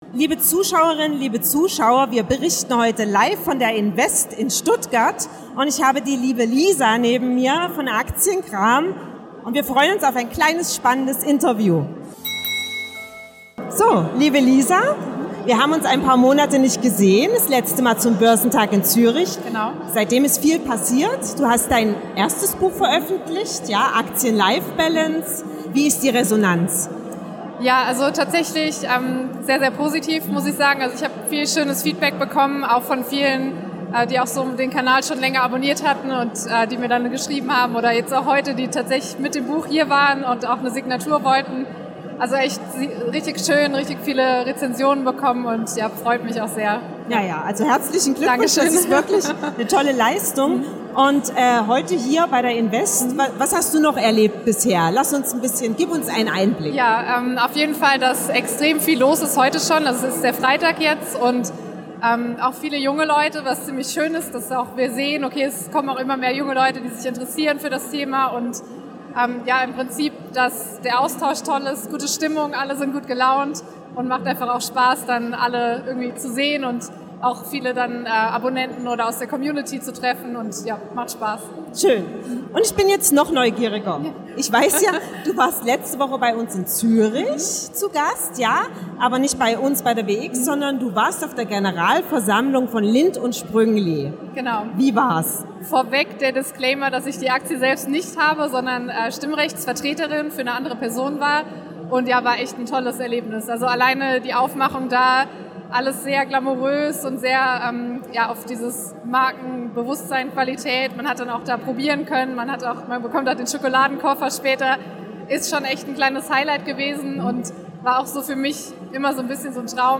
Finanzmesse in Stuttgart, die Resonanz auf ihr Debüt-Buch